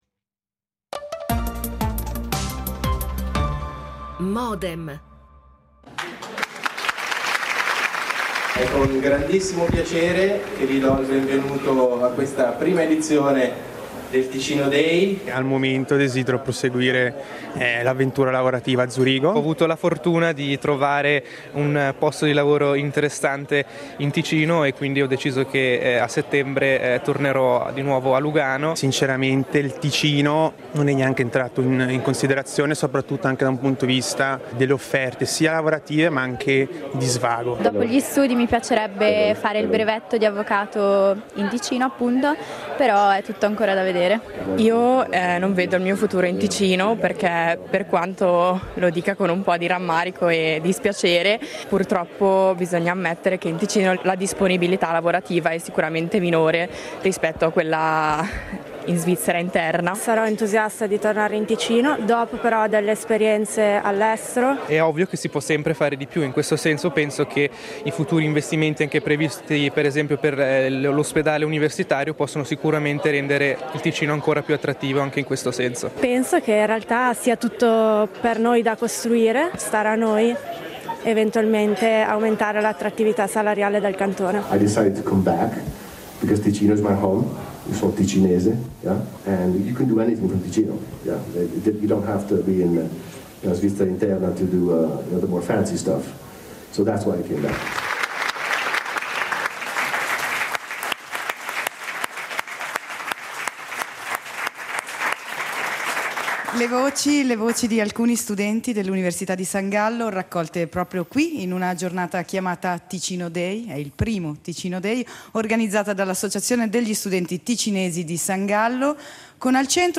Dibattito registrato in occasione del Ticino Day della SGOC, l’associazione gli studenti ticinesi di San Gallo.